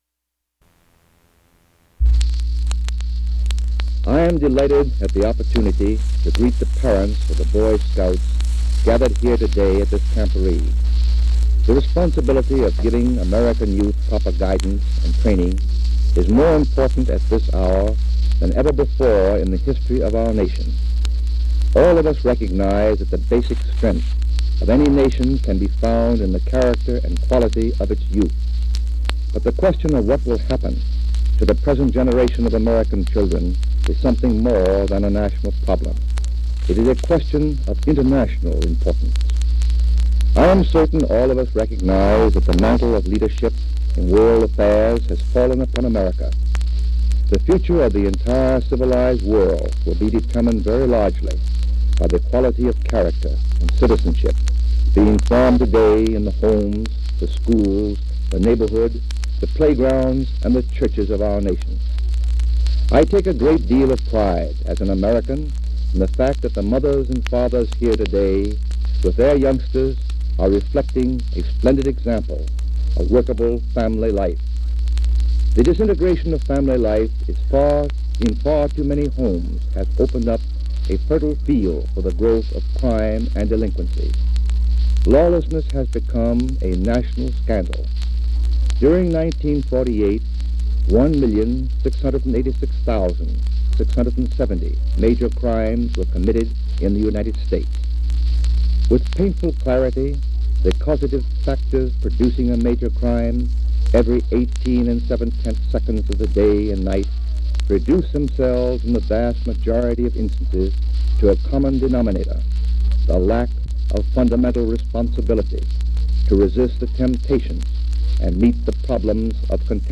J. Edgar Hoover gives a speech at the acceptance of honorary award from the American Legion, in San Francisco